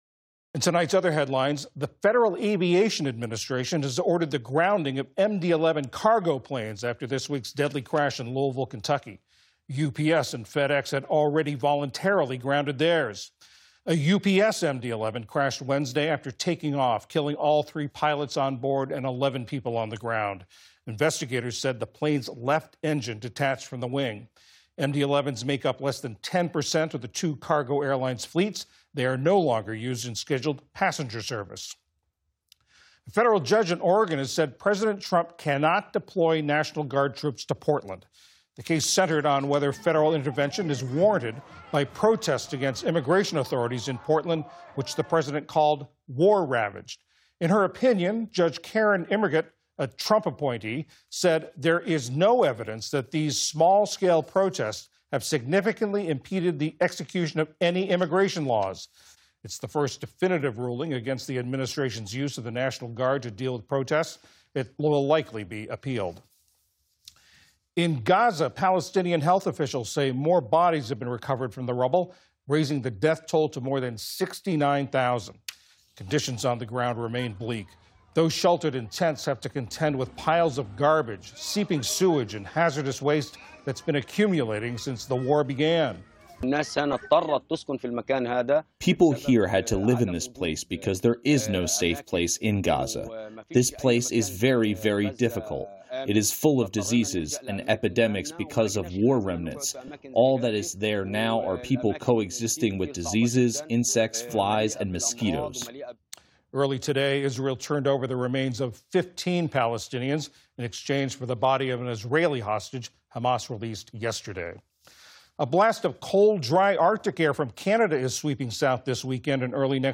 News Wrap: UPS and FedEx ground MD-11 cargo planes after deadly crash 3:10